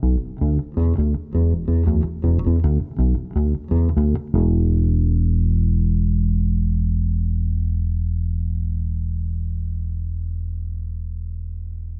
贝斯融合7
描述：适用于许多流派的无品类爵士贝斯的旋律
Tag: 80 bpm Fusion Loops Bass Guitar Loops 2.02 MB wav Key : E